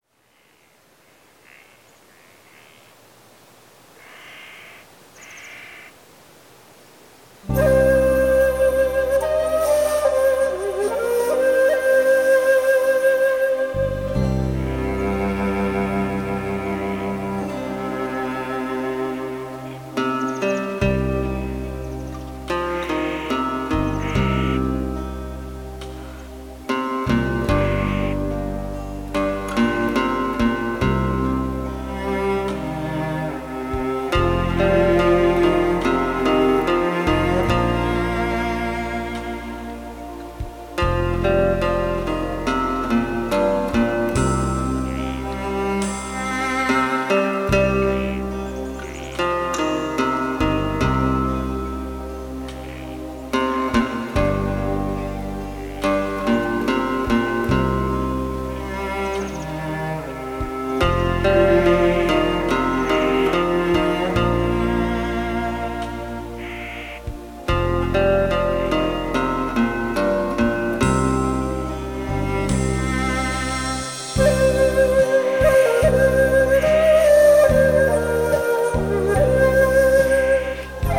应用世界音乐风格的编曲，加上韩国国
了以韩乐器乐『伽倻琴、奚胡、大令、杖鼓、牙筝、
ocraina....』，还包括中国二胡、
以及特殊的乐器伽倻琴和Ocarina等，